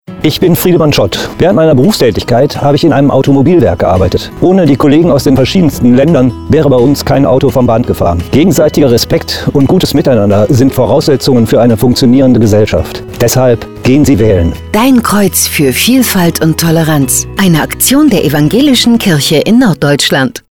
Um möglichst viele Menschen zu erreichen, haben wir Radiospots in norddeutschen Sendern geschaltet, die wir in Kooperation mit dem Evangelischen Presseverband Nord produziert haben. Sechs Menschen aus unserer Landeskirche haben mitgemacht und ein „Testimonial“ eingesprochen.